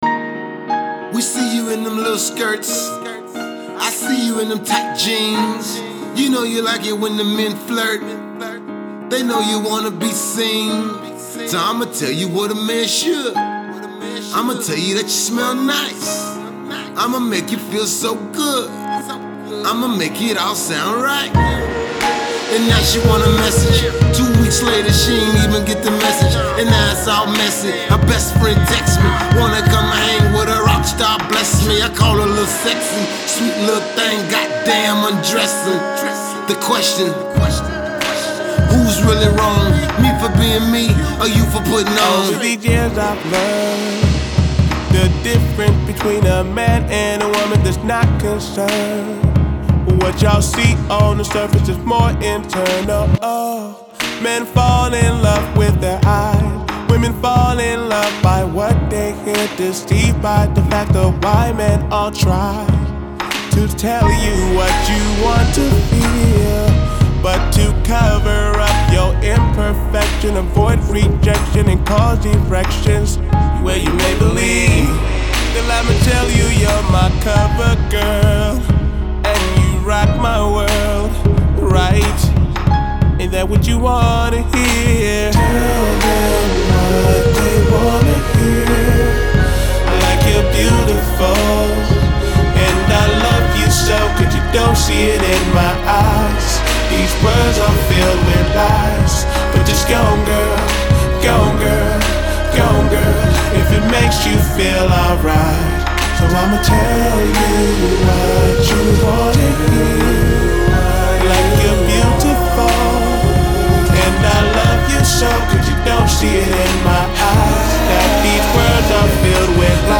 Soul trap meets R&B soul.